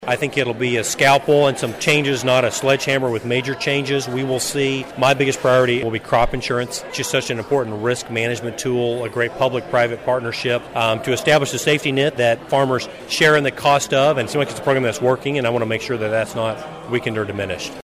Following his remarks, KMAN followed up with Rep. Mann on the 2023 Farm Bill, which is still about a year away from being finalized.